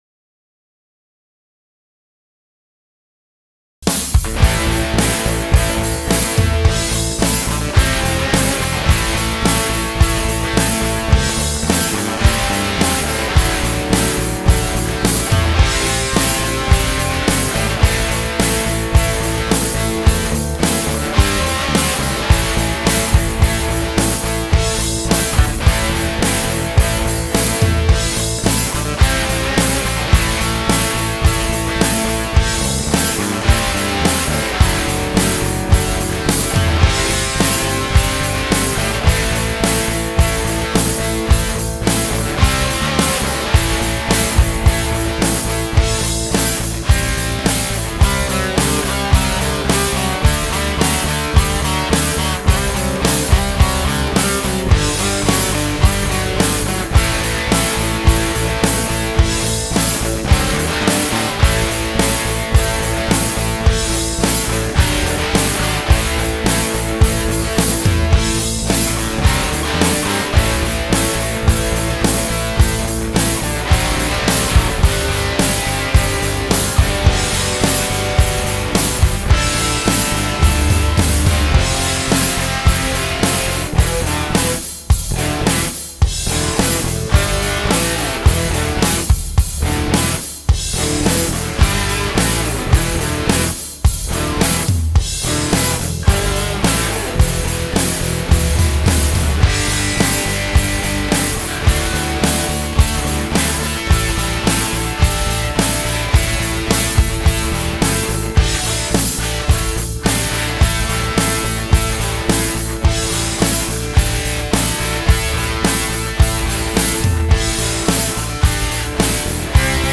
Instrumentalen Rocknummern
LYRICS & LEAD VOCALS